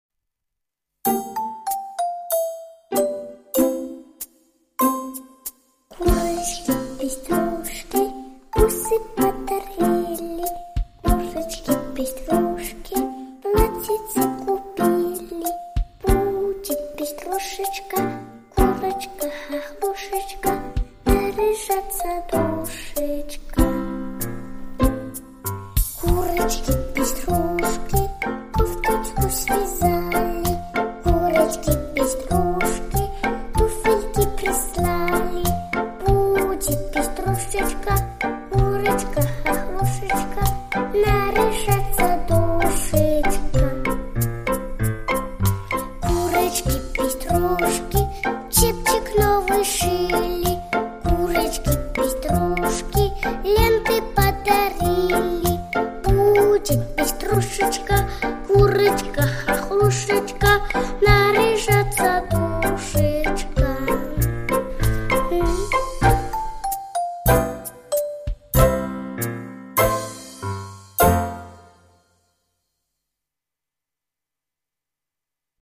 Курочка - песенка про животных - слушать онлайн